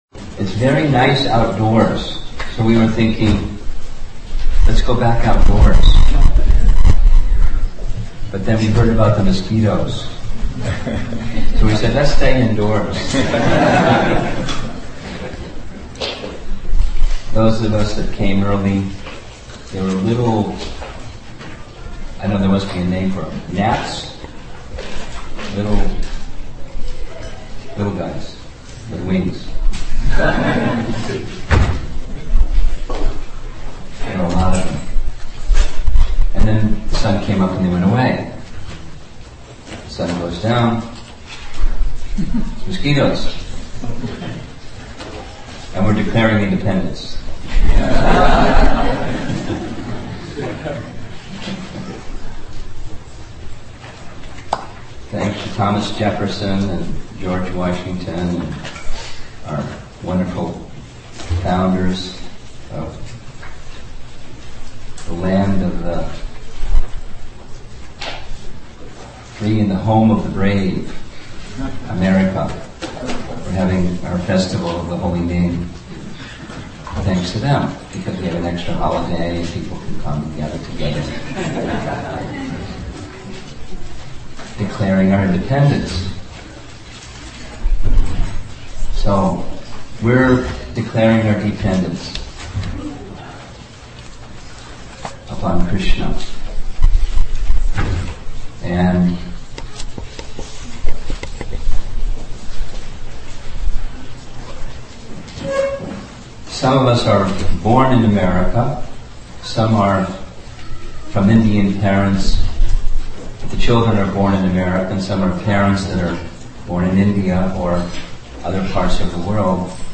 313_Declaring-Dependence-On-The-Holy-Name-Naperville-IL.mp3